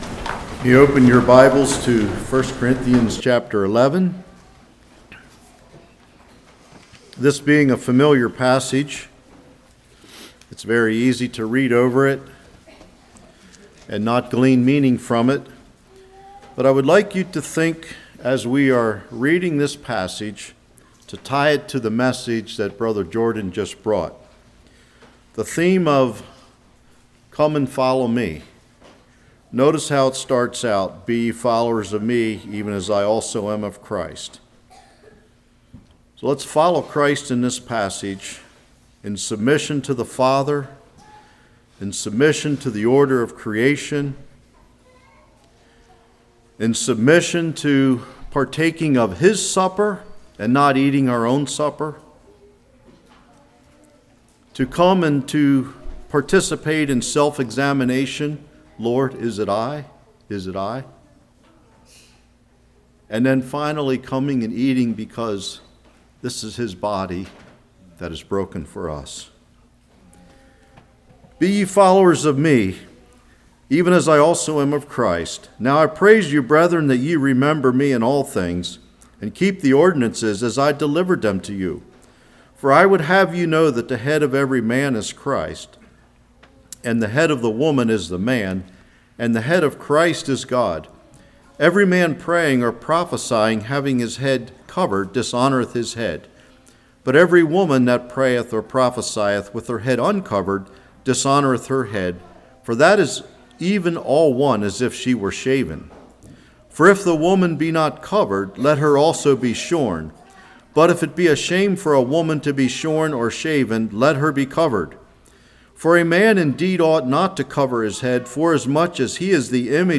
Self Examination Sermon